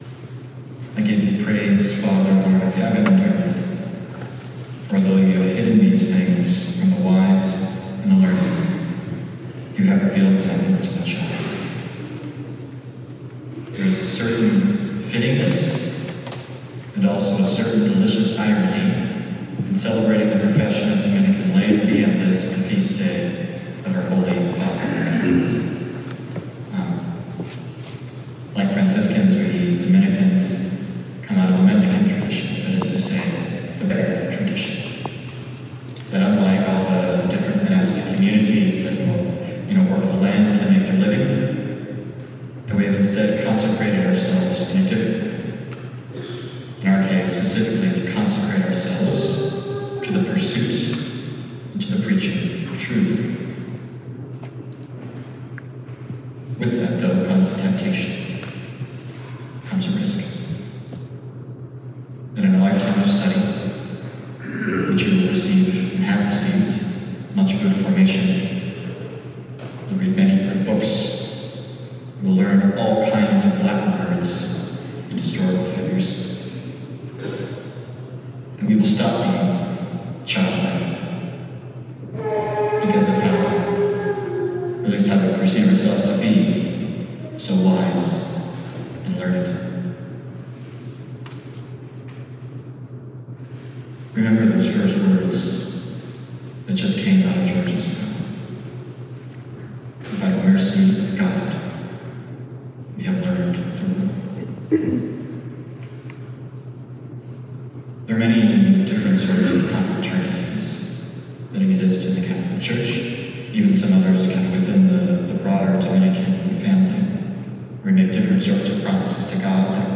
2025 Day of Recollection – Homily
This is the homily of the Mass of Commitment.